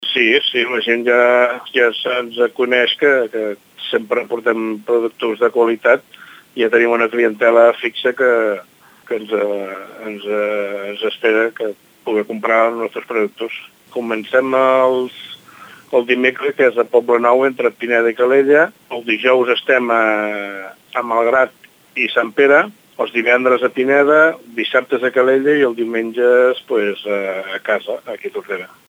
L’Info Mercat de Ràdio Tordera parla amb els marxants del mercat dels diumenges.